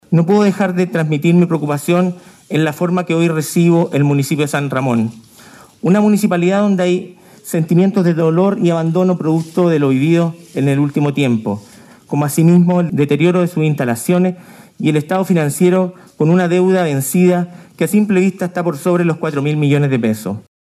En su primer discurso, Toro acusó un déficit superior a los $4 mil millones y dijo que la municipalidad cuenta con un exceso de personal, que hace inviable pagar los salarios.